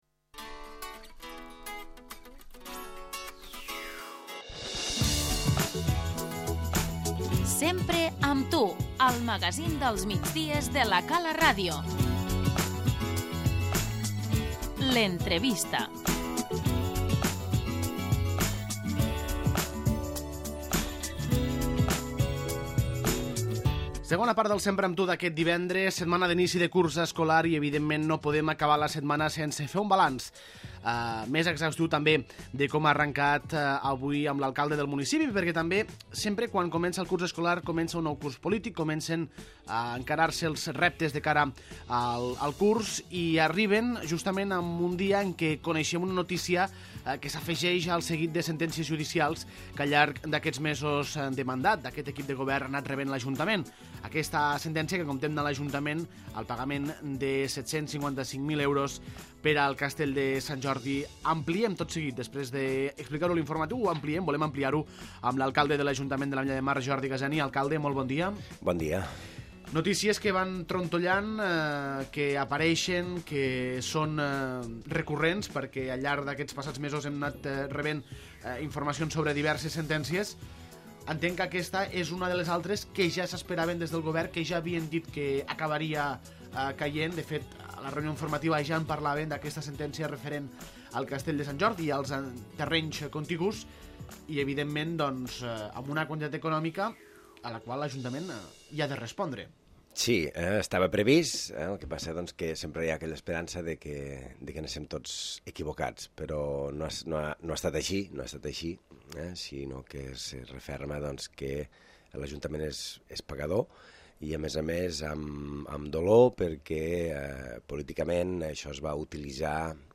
L'entrevista - Jordi Gaseni, alcalde de l'Ametlla de Mar